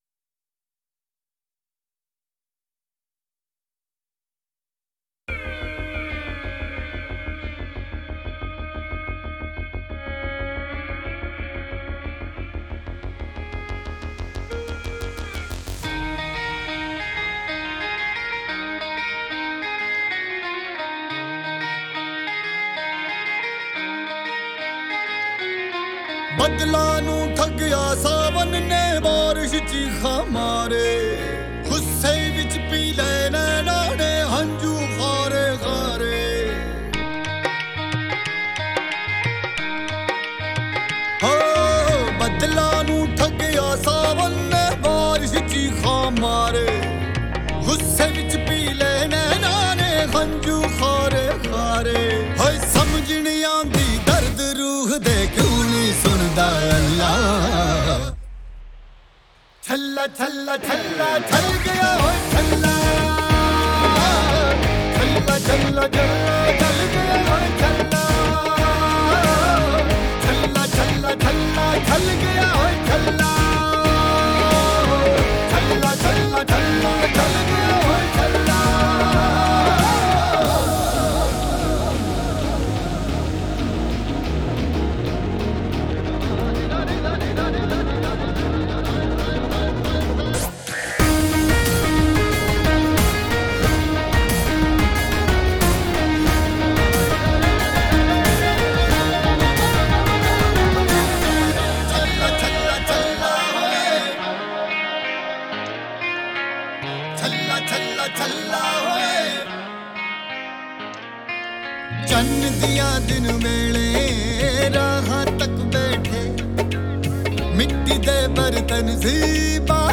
آهنگ هندی شاد